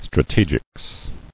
[stra·te·gics]